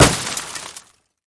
Projectile Stone Impact Sound.wav